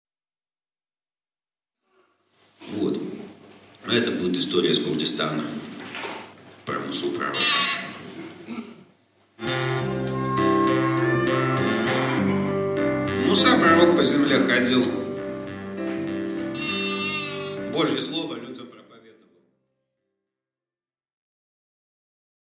Аудиокнига Пророк и пастух | Библиотека аудиокниг
Прослушать и бесплатно скачать фрагмент аудиокниги